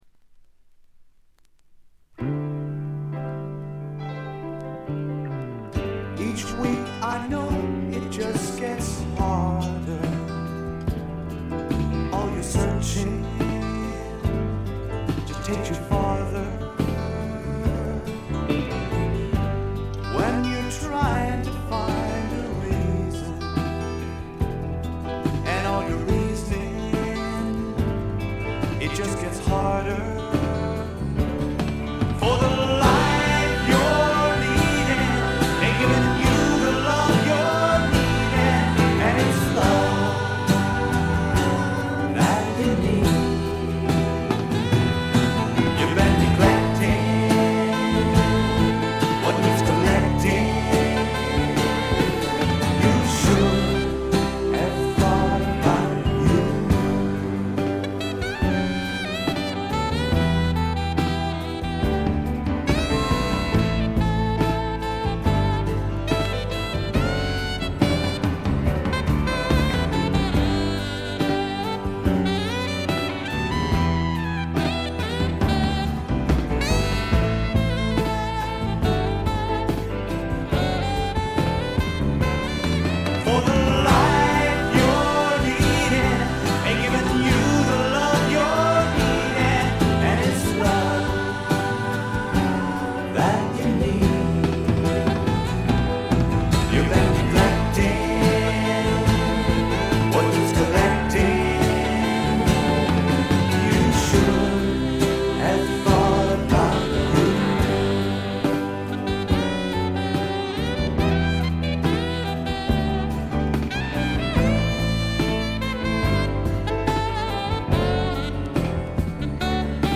英国フォークロックの基本。
試聴曲は現品からの取り込み音源です。